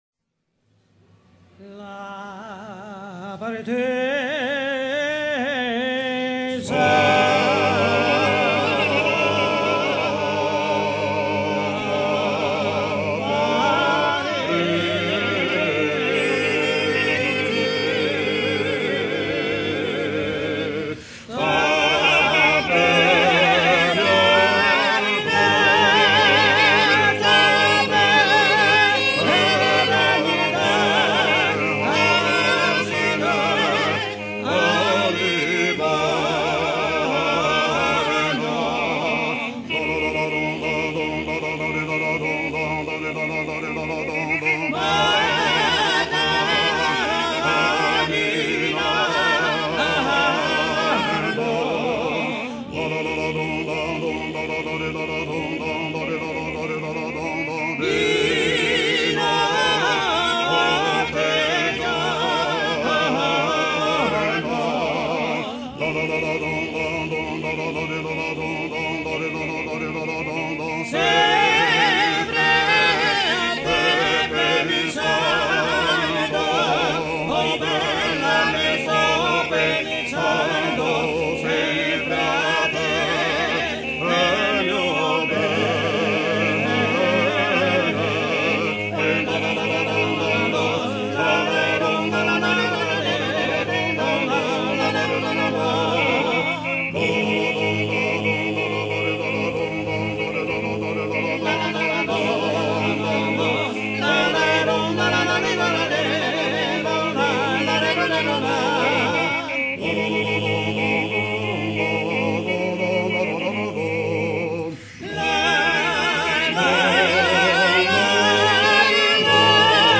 ancien trallalero
Pièce musicale éditée